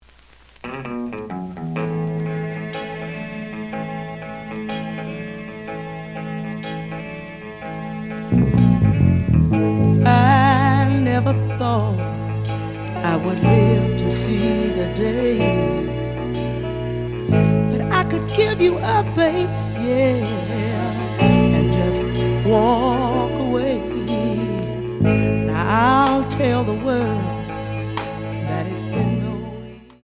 The audio sample is recorded from the actual item.
●Genre: Funk, 70's Funk
B side plays good.)